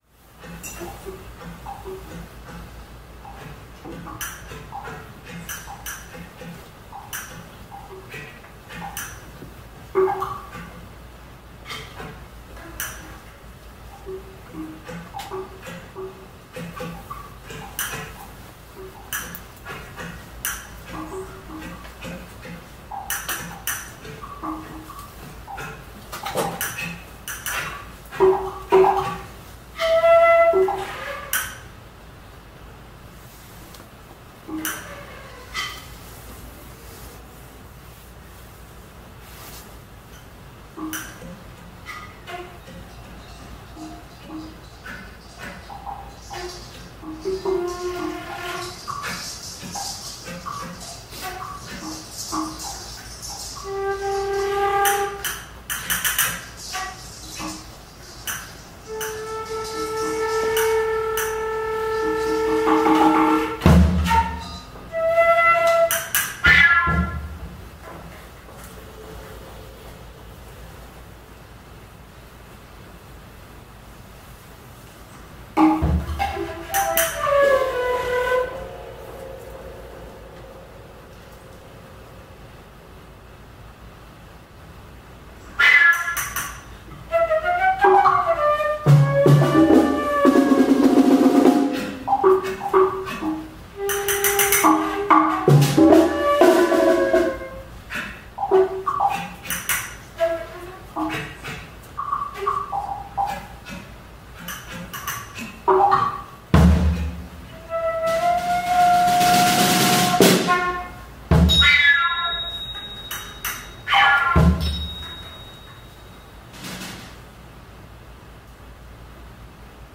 Instrumentation: flute and percussion